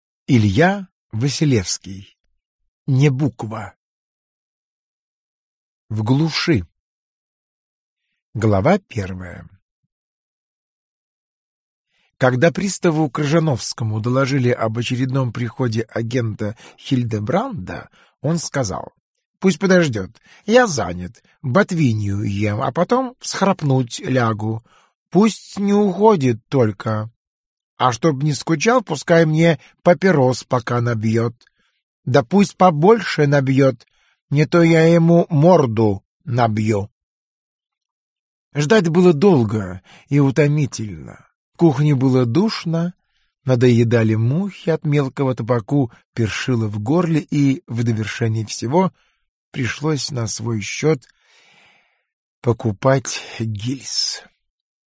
Аудиокнига Юмористические рассказы русских писателей в исполнении Валерия Гаркалина | Библиотека аудиокниг